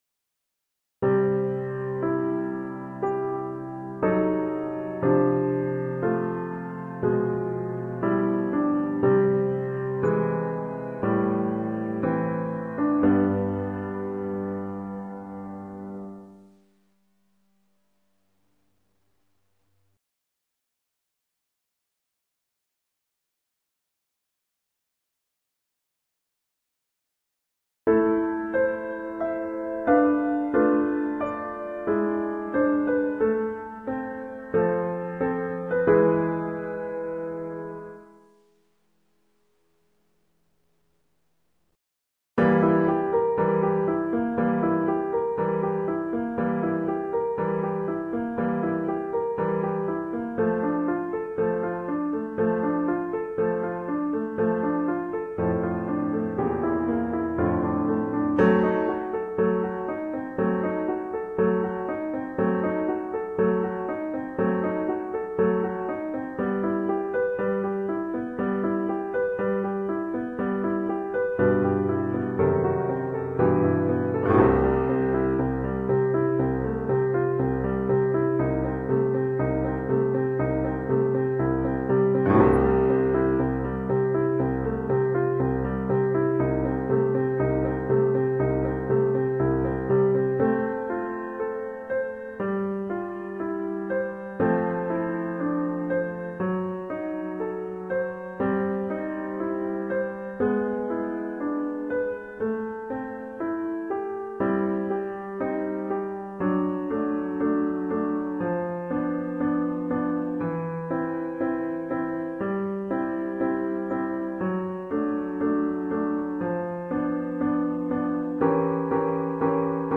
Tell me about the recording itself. (Audio generated by Sibelius/NotePerformer)